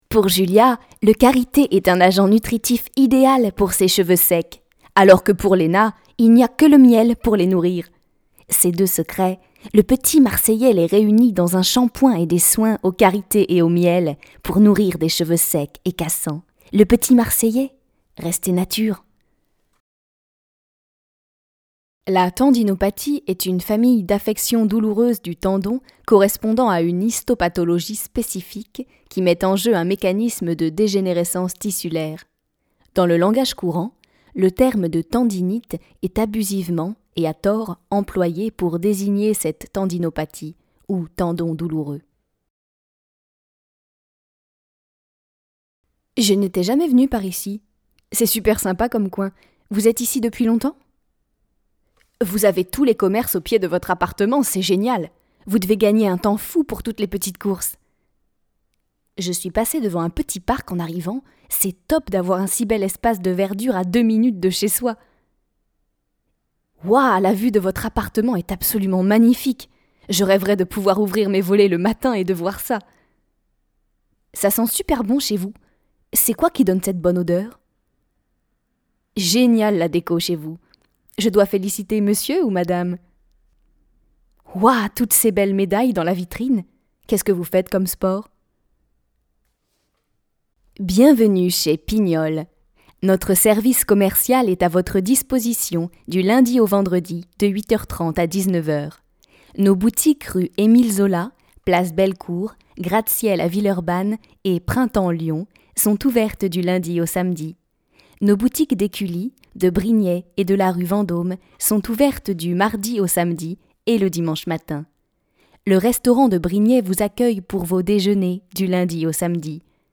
Voix off
Narratrice
- Mezzo-soprano